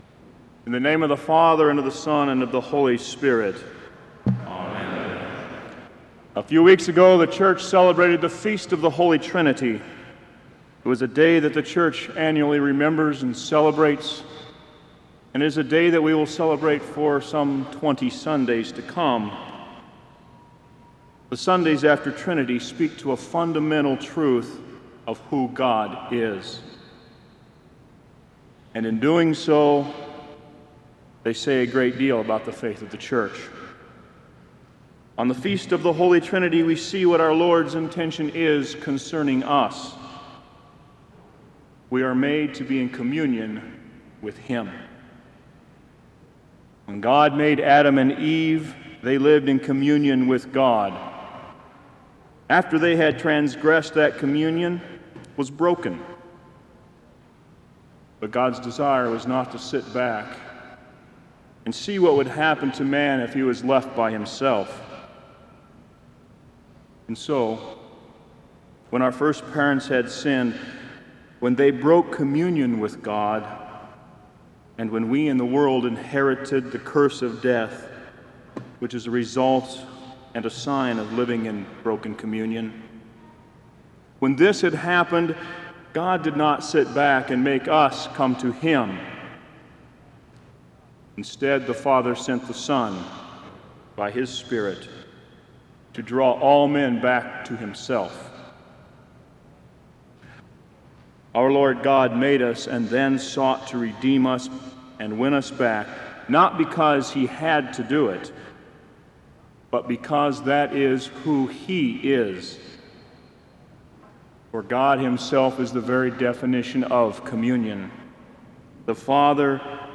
Kramer Chapel Sermon - June 15, 2005